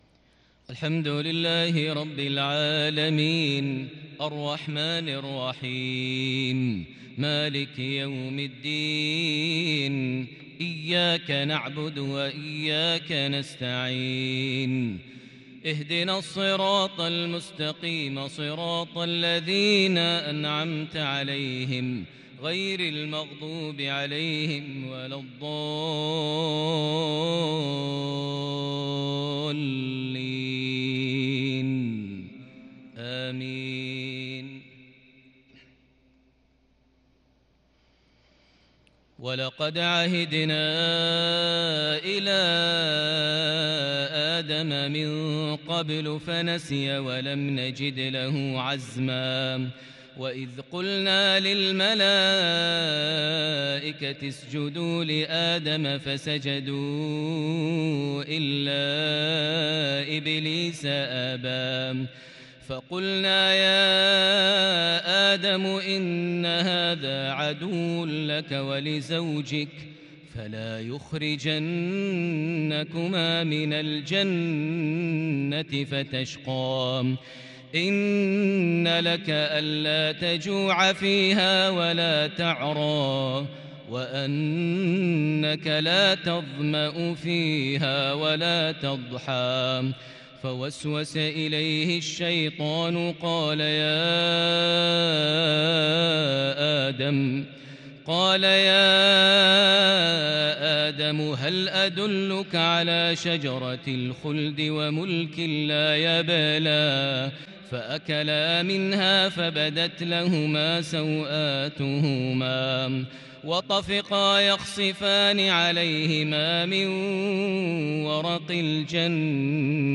عشائية تتجلّى بالإبداع بالكرد الفذ من سورة طه | الخميس 28 ذو القعدة 1442هـ > 1442 هـ > الفروض - تلاوات ماهر المعيقلي